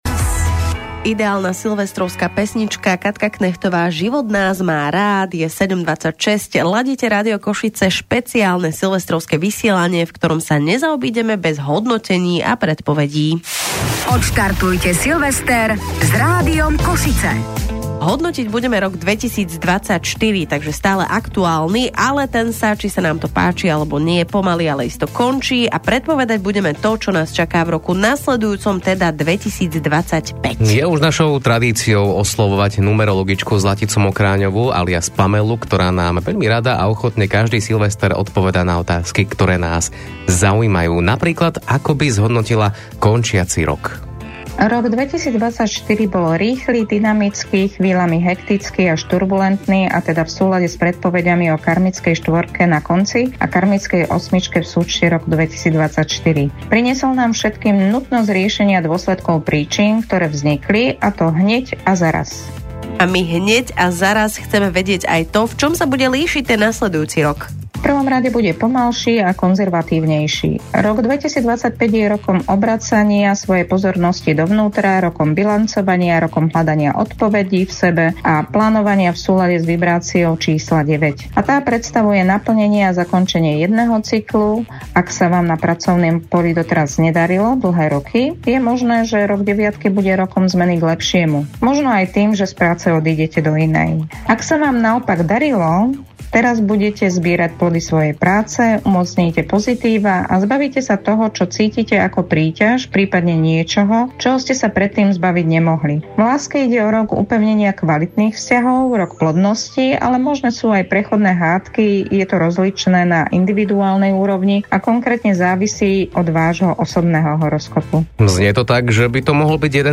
Predikcie na rok 2025 odozneli na Silvestra v roku 2024 v Rádiu Košice.